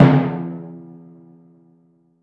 • Ambient Tom Drum Sound D Key 26.wav
Royality free tom drum sound tuned to the D note. Loudest frequency: 369Hz
ambient-tom-drum-sound-d-key-26-rLT.wav